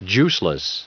Prononciation du mot juiceless en anglais (fichier audio)
Prononciation du mot : juiceless